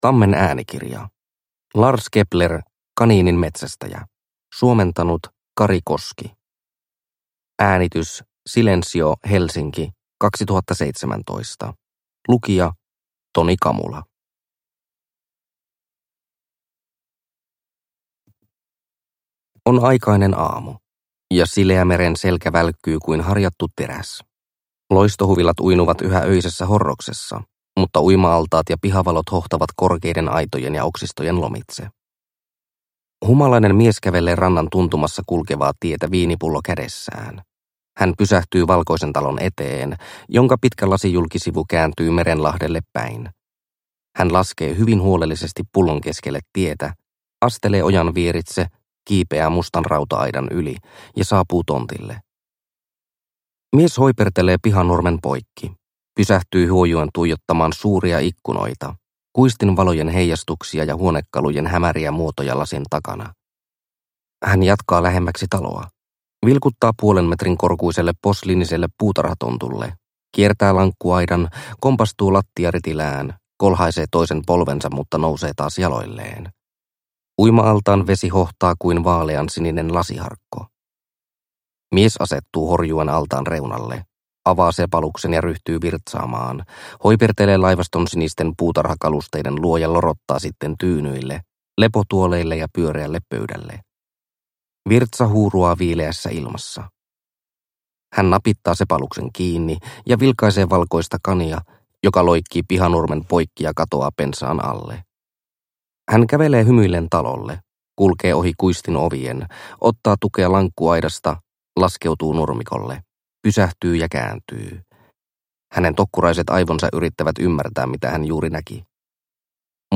Kaniininmetsästäjä (ljudbok) av Lars Kepler